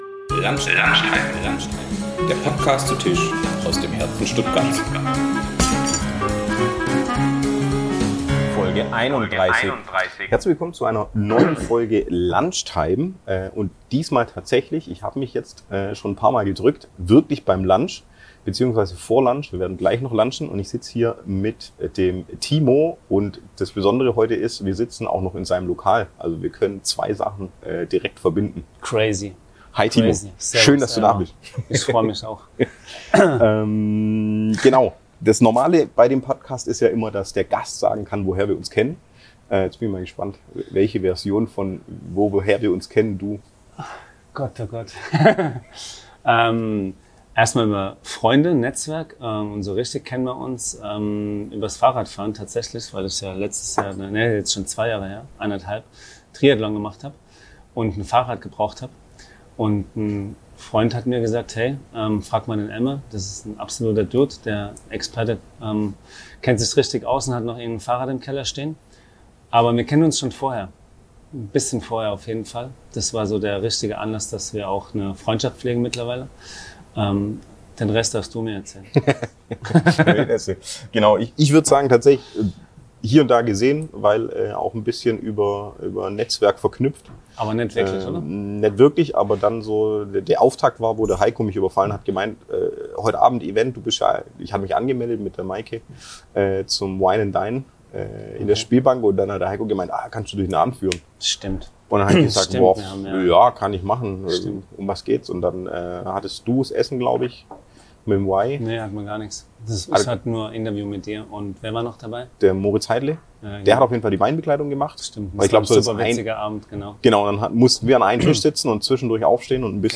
In dieser Folge gehts sitzen wir im eigene Restaurant von Timo und zum (bei Aufnahme) neu eingeführten Business-Lunch zusammen – also alles voll auf Konzept würde ich sagen!
Eins bleibt aber gleich: Wir nehmen etwas abgesetzt zum Essen auf, um euch Schmatz-Geräusche zu ersparen. Bei der Aufnahme setzten wir natürlich beim Kennenlernen an und lassen uns dann "natürlich" durch Fußball, Sport und "Leben-Danach"-Themen treiben.